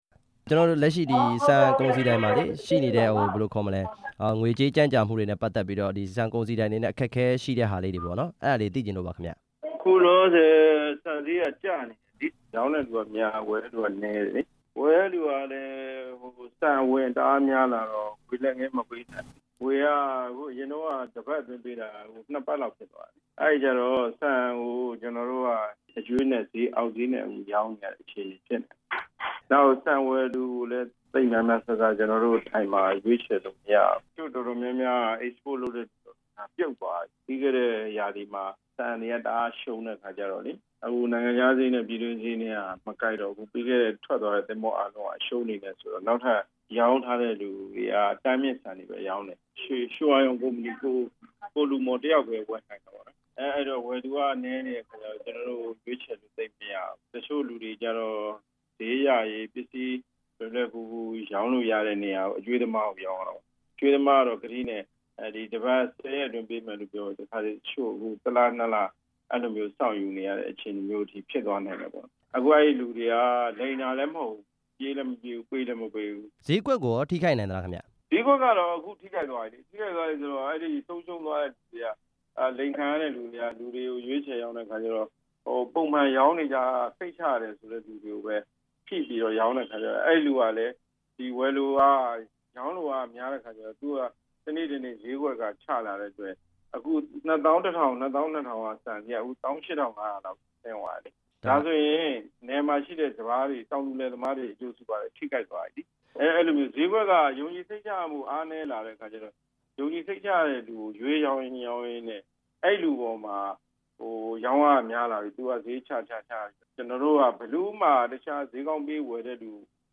ဆန်ဈေးကွက်အကြောင်း မေးမြန်းချက်